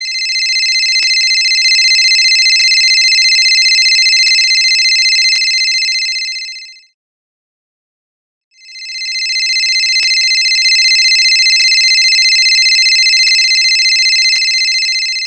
Categoria Alarmes